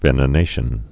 (vĕnə-nāshən)